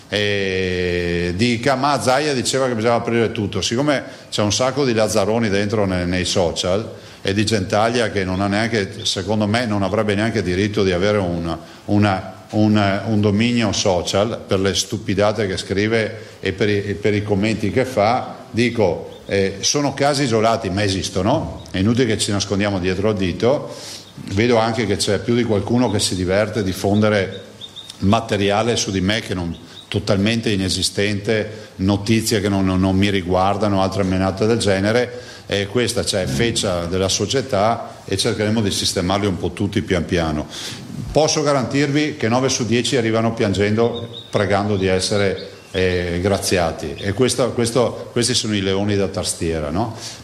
I LEONI DA TASTIERA, RIAPERTURE, MASCHERINE E ALTRO DALLA CONFERENZA STAMPA DI ZAIA